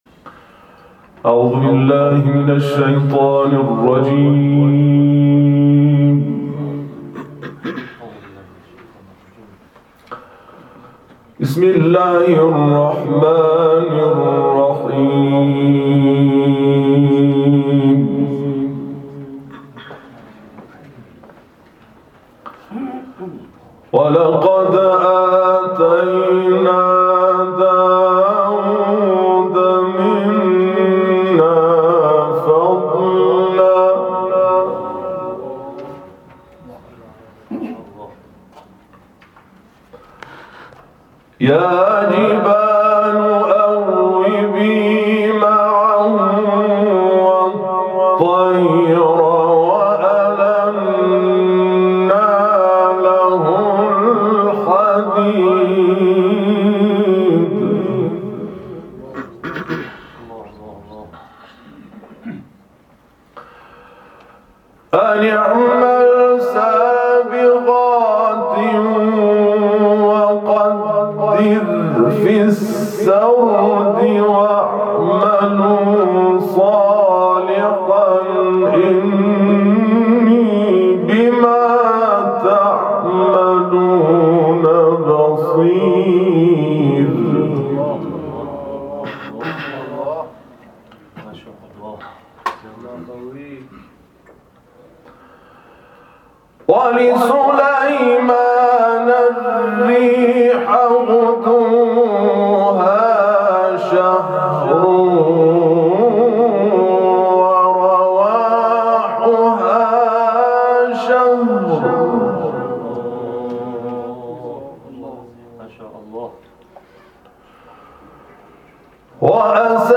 جدیدترین تلاوت
در مراسم ششمین سالگرد برپایی کرسی‌های تلاوت شورای عالی قرآن
از رادیو قرآن نیز به صورت زنده روی آنتن رفت